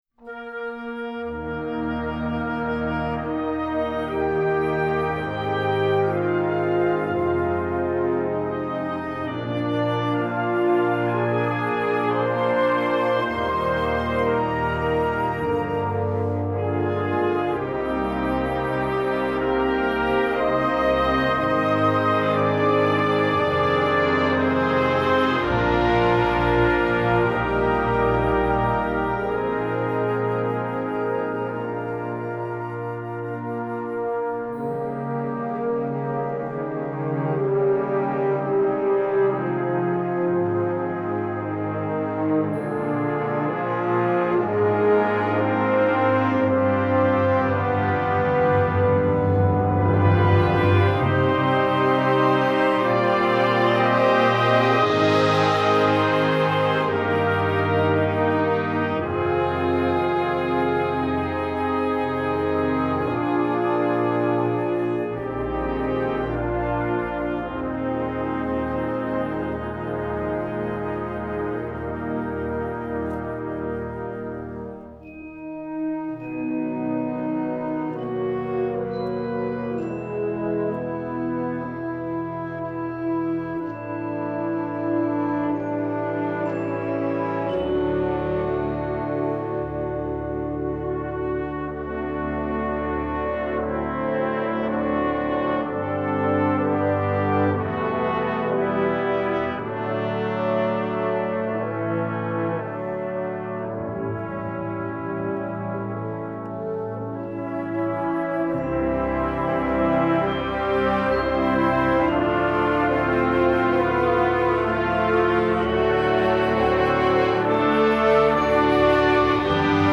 23 x 30,5 cm Besetzung: Blasorchester PDF